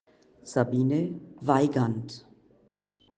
Wie spricht man eigentlich den Namen richtig aus,